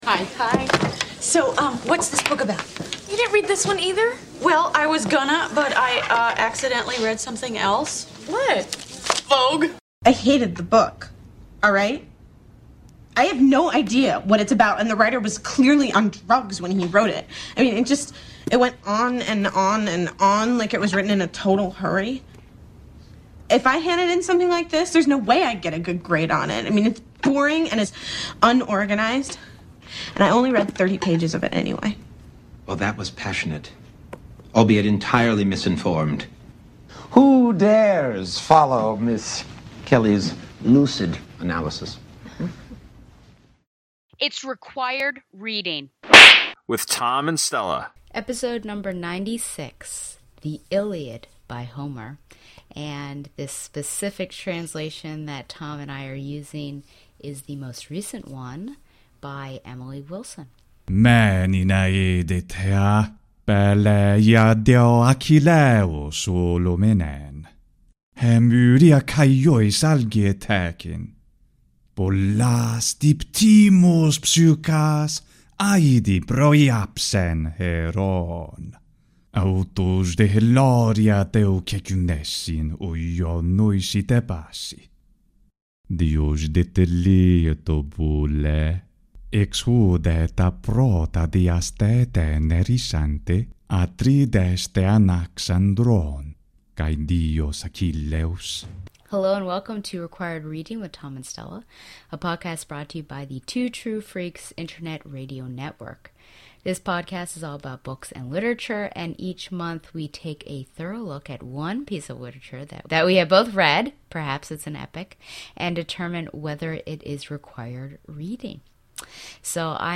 is two teachers talking about literature. Each episode, we will be taking a look at a single work, analyzing it, critic…